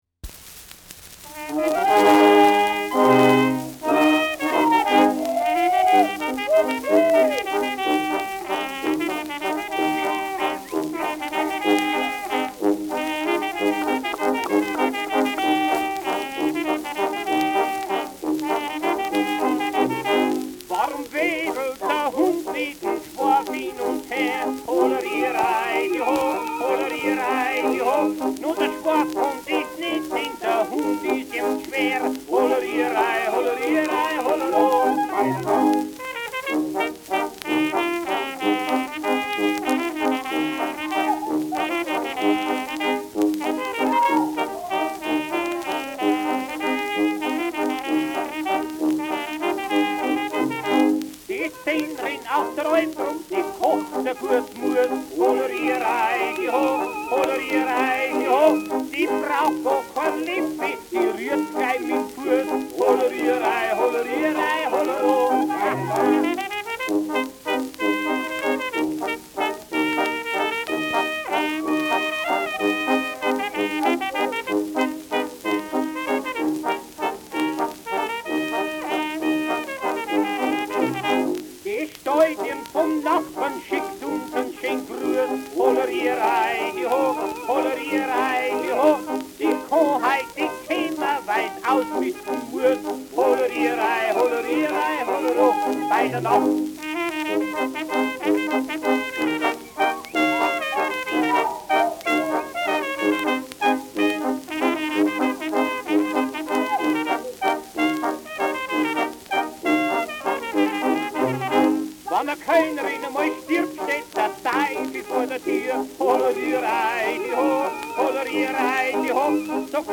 Schellackplatte
leichtes Rauschen : präsentes Knistern
Isartaler Bauernkapelle (Interpretation)
[München] (Aufnahmeort)